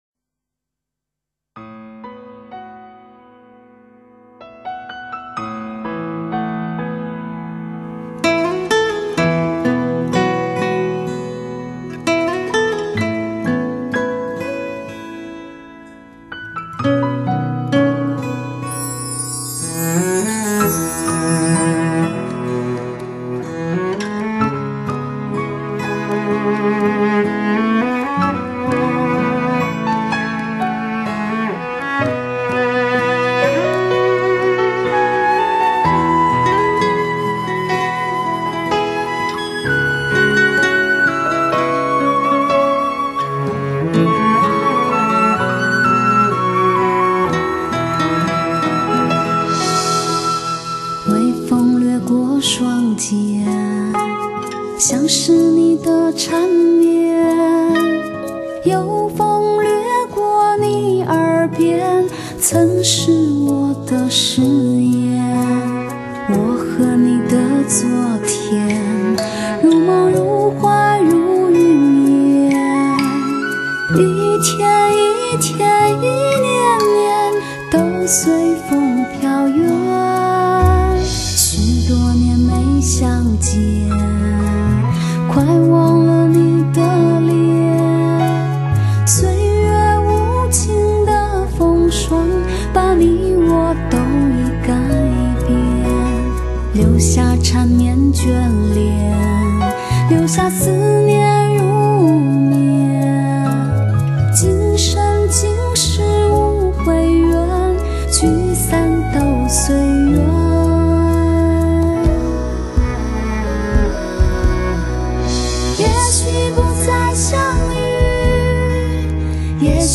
无比真实的发烧女音，借由顶级发烧音响技术-DTS多声道环绕音效技术而更显细腻温醇。